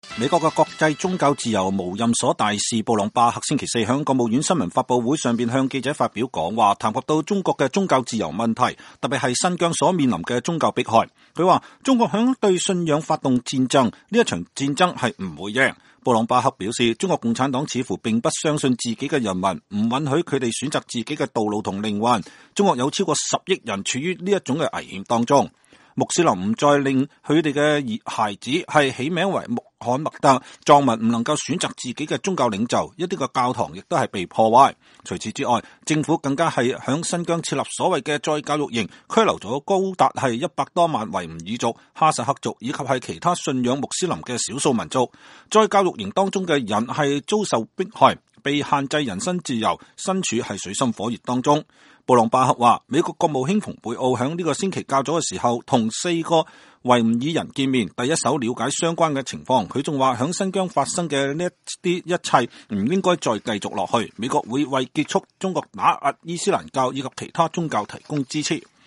美國的國際宗教自由無任所大使布朗巴克（Sam Brownback)星期四在國務院新聞發布會上向記者發表講話，談及中國的宗教自由問題，特別是新疆所面臨的宗教迫害。他說，中國是在對信仰發動戰爭，這場戰爭是不會贏的。